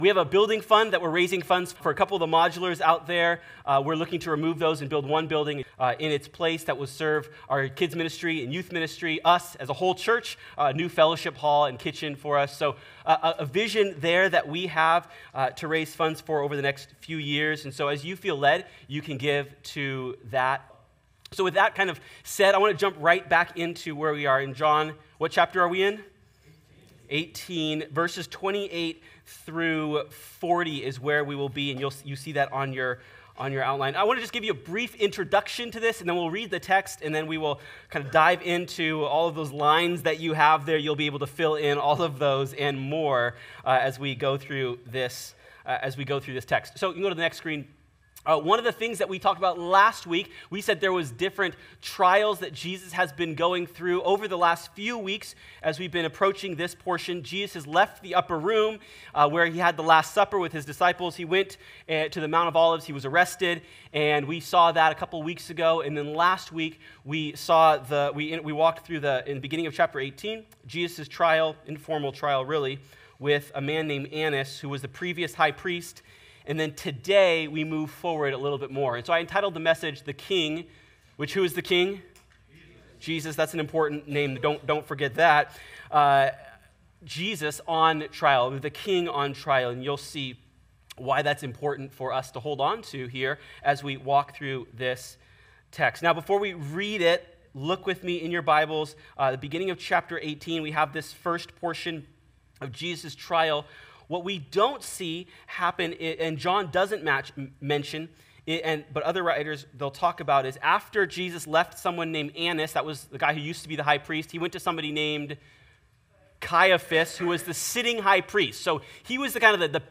In our text today, we find Jesus standing before Pilate, a powerful Roman governor who questions Jesus and ends up caving to the desire of the people. Sermon